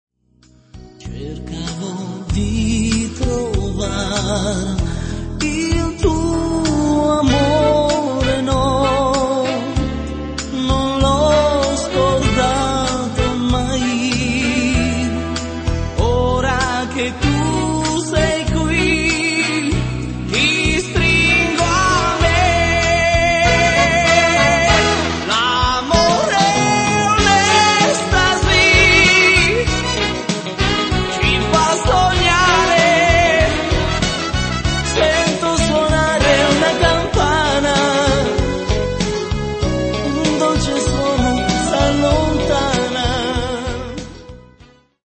lento